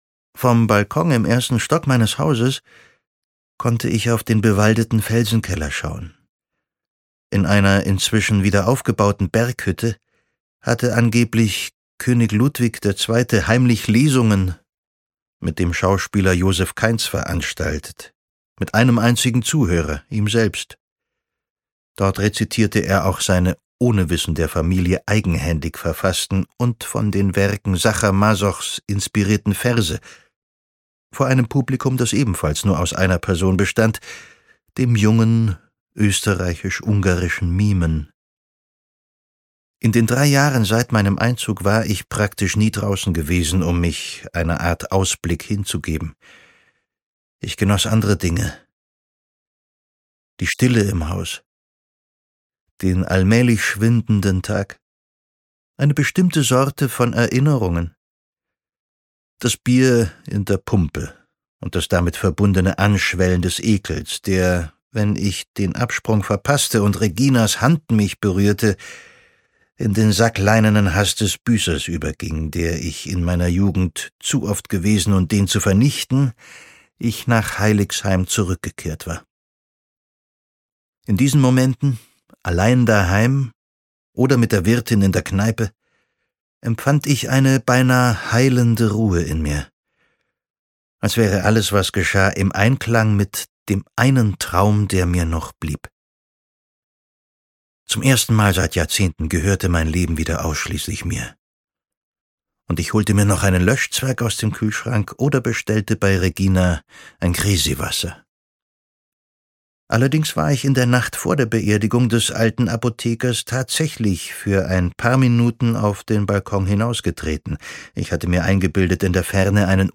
Nackter Mann, der brennt - Friedrich Ani - Hörbuch